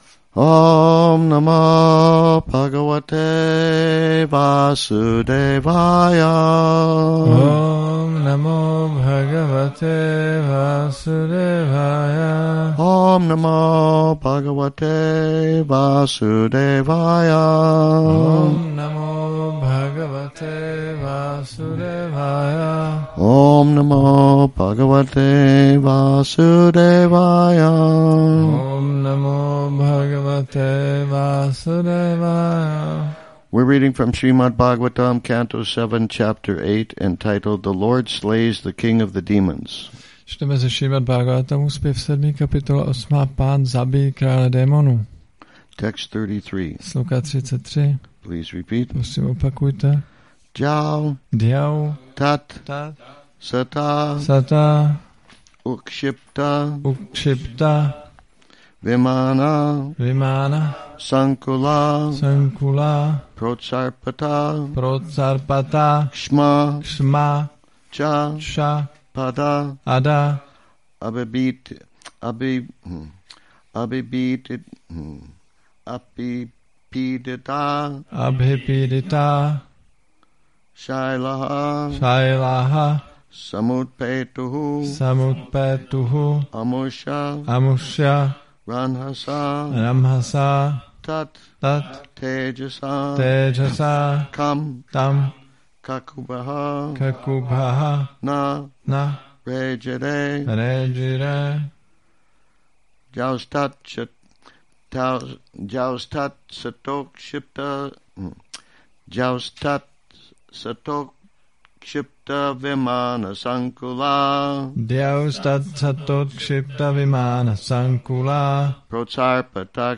Šrí Šrí Nitái Navadvípačandra mandir
Přednáška SB-7.8.33